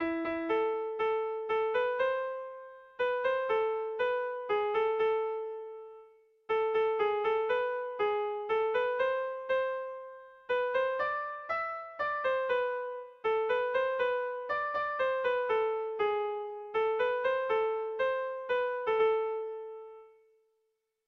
Melodías de bertsos - Ver ficha   Más información sobre esta sección
Barkoxe < Basabürüa < Zuberoa < Euskal Herria
ABDE